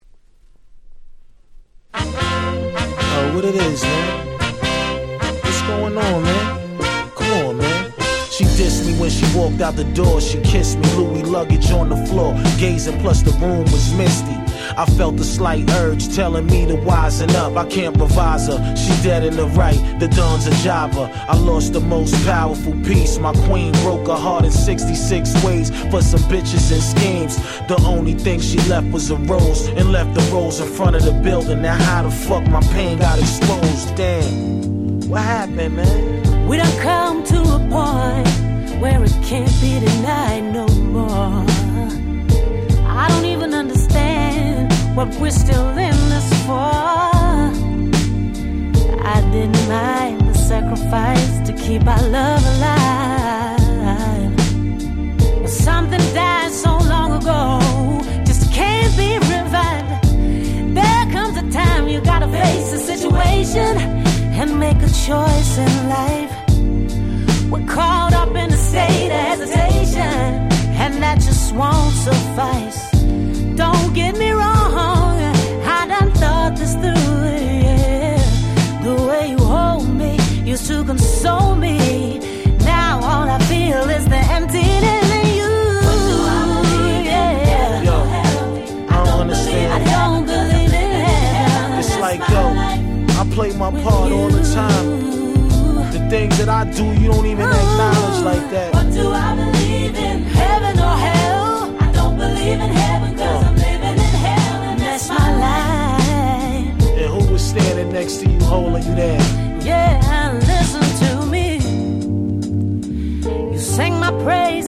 06' Super Nice Neo Soul / R&B !!